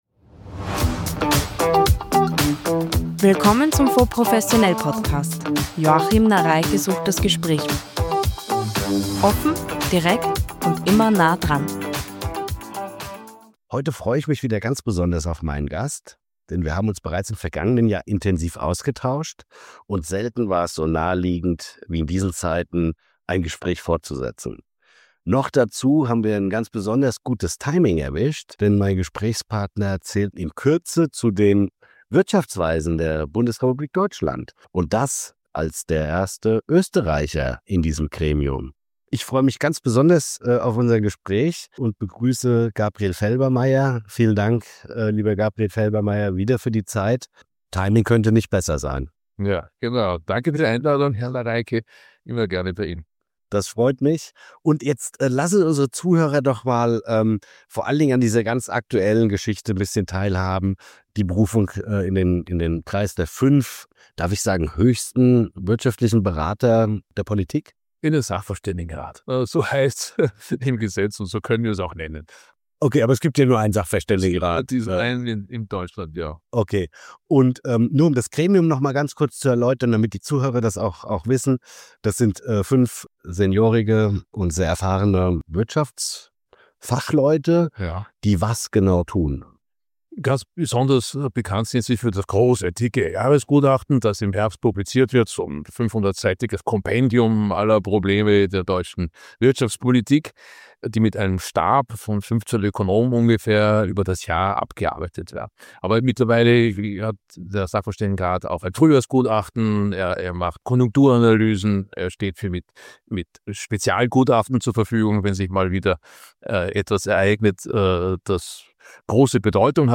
sucht das Gespräch mit Gabriel Felbermayr ~ FONDS professionell PODCAST